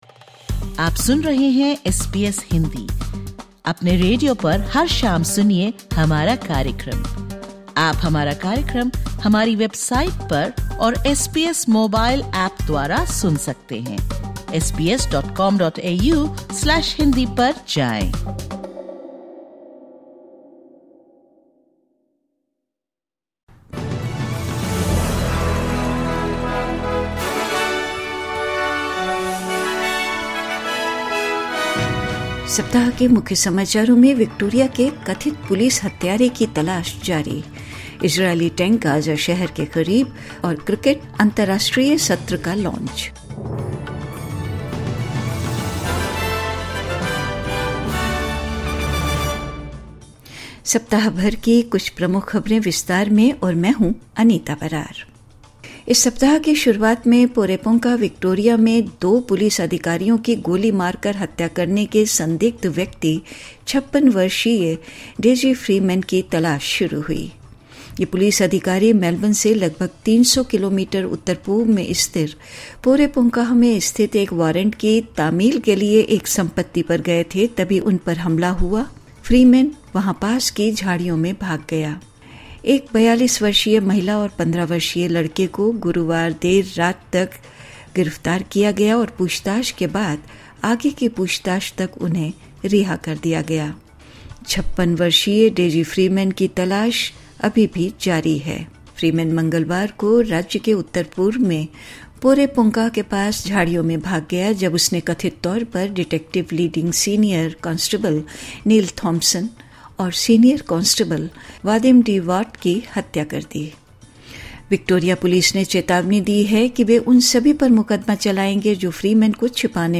साप्ताहिक समाचार 30 अगस्त 2025